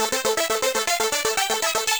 Index of /musicradar/8-bit-bonanza-samples/FM Arp Loops
CS_FMArp B_120-A.wav